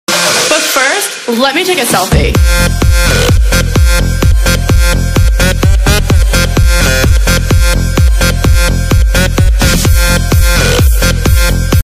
10-saniyelik-sark-kesiti_MRAHjWE.mp3